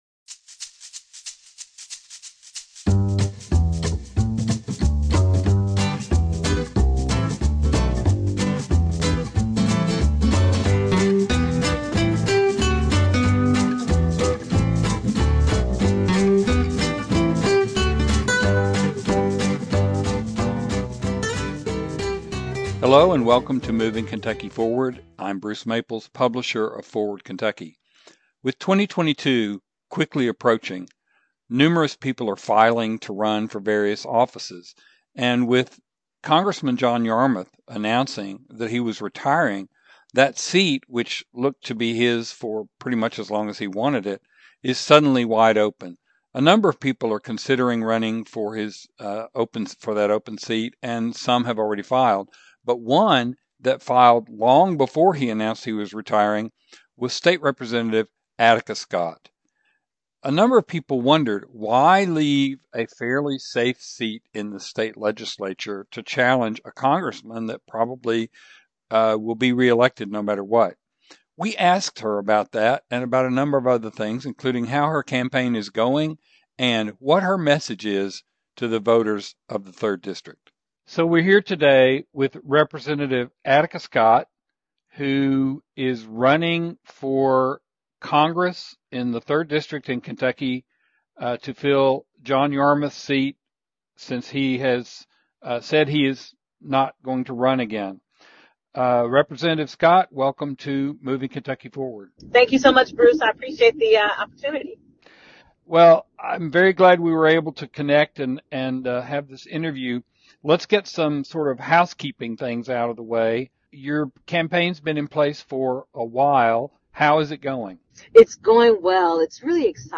An interview with Attica Scott
In this episode of Moving Kentucky Forward, we interview state Rep. Attica Scott about her campaign for Congress in Kentucky's 3rd district. We ask her why she decided to run, how her campaign is going, and what key issues she wants to address if elected. Give a listen to this progressive champion as she speaks with passion and vigor about what her campaign is about.